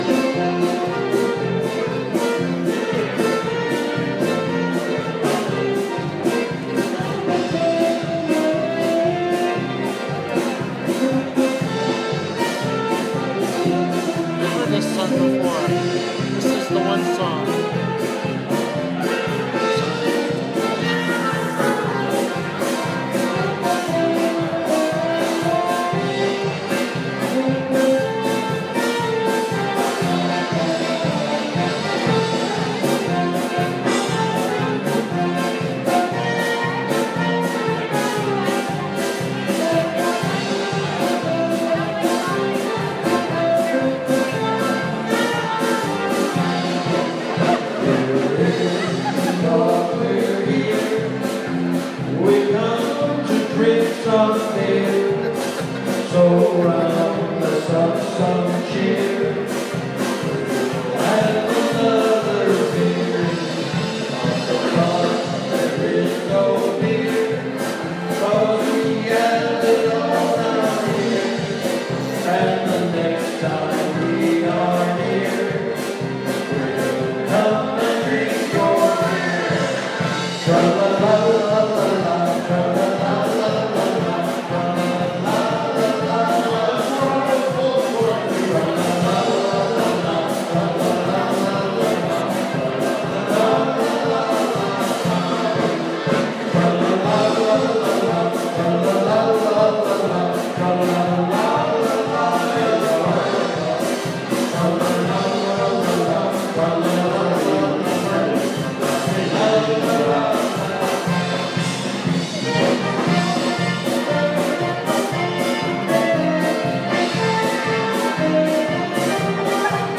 A song about beer performed at Oktoberfest at Snowbird Ski Resort in Snowbird, Utah.